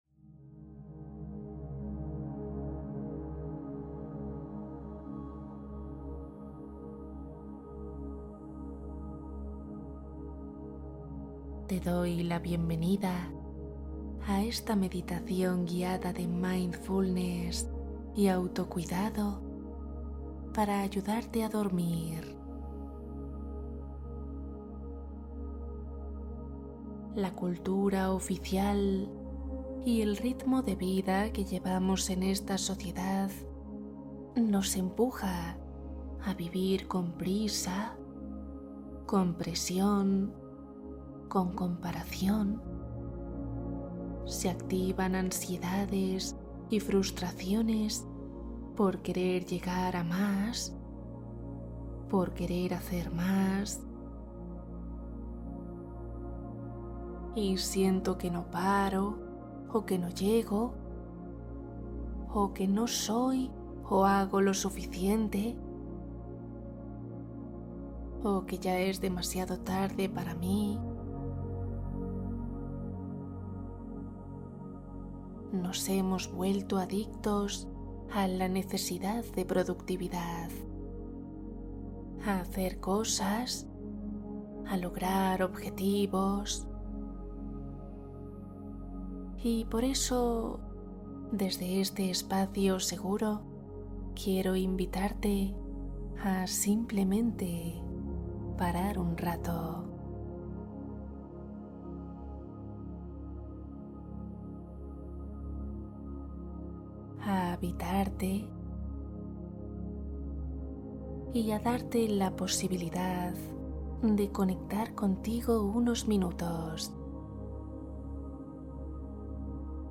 Mindfulness para dormir Relajación guiada para sueño rápido